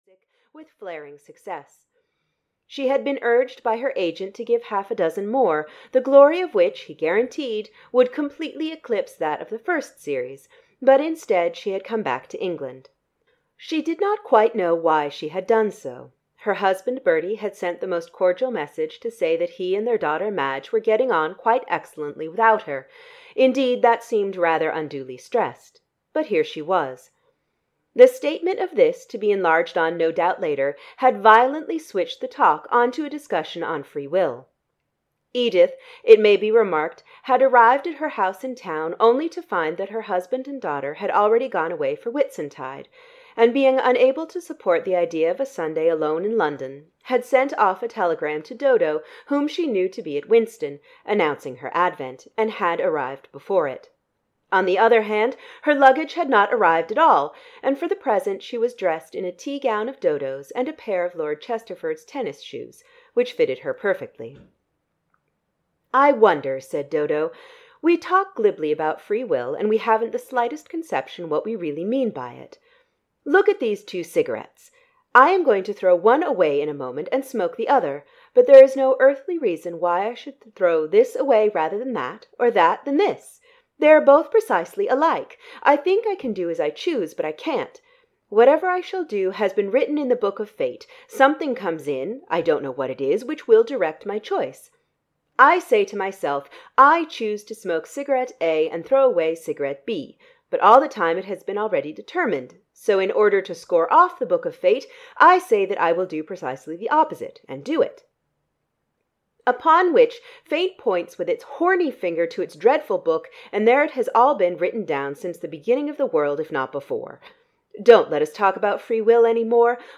Dodo Wonders (EN) audiokniha
Ukázka z knihy